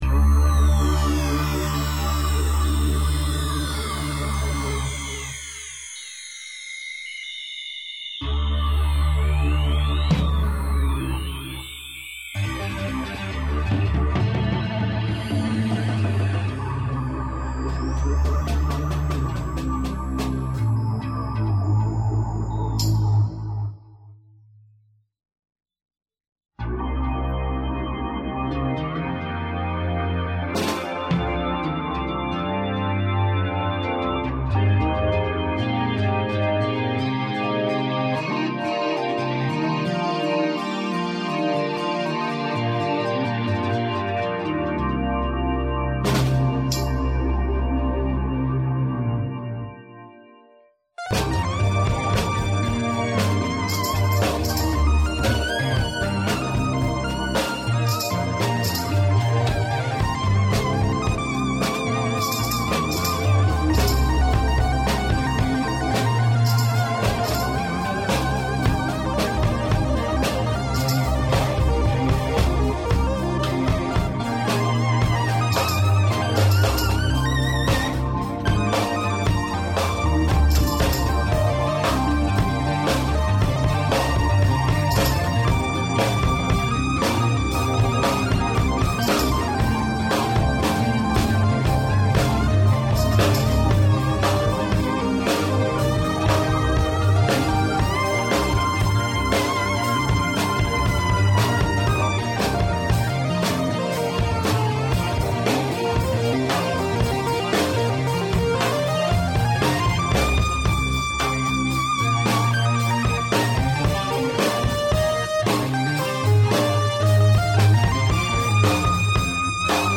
drums
organ